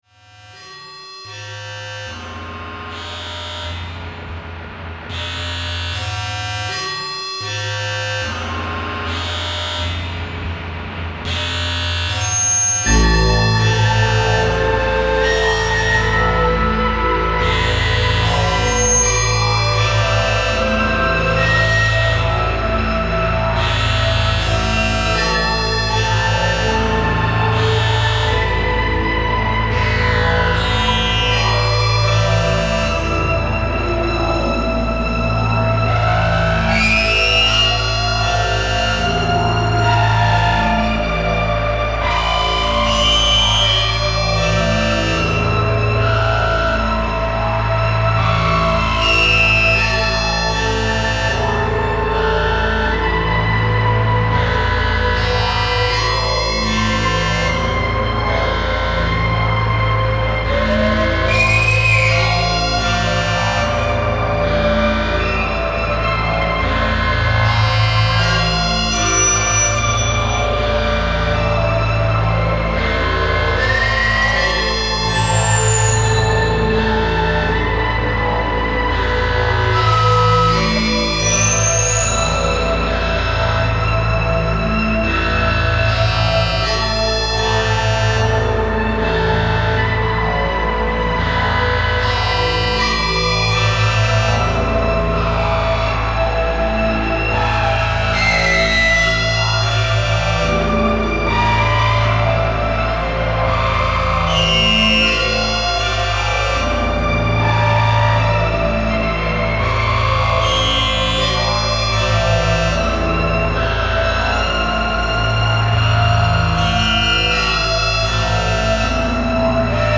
Der Dübreq CPM DS-2 (der kleine schwarze in der Mitte des Fotos) ist ein zweistimmiger Drone Synthie, der mir im Moment sehr viel Freude bereitet. Hier ein erstes Tonbeispiel (Hintergrundsounds): Dübreq_250509aFortsetzung folgt!